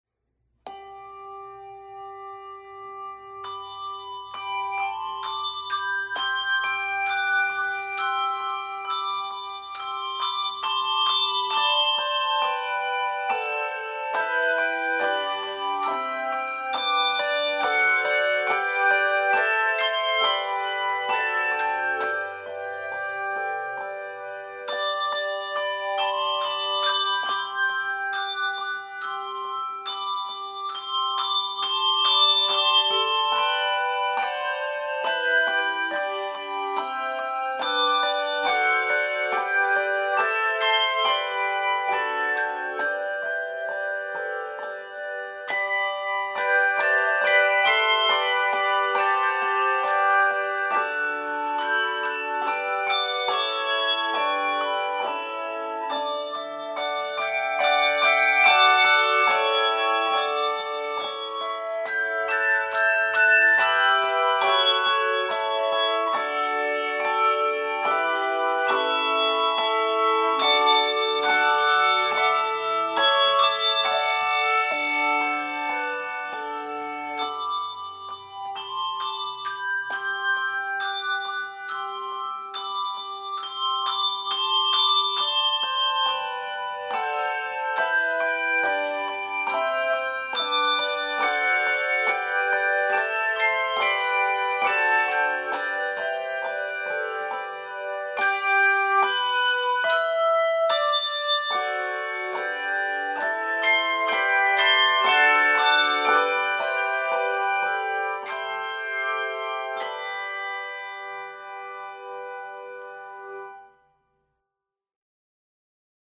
is a well-known lyrical piece with a haunting melody.
is 42 measures in the key of G Major
is 36 measures in the key of c minor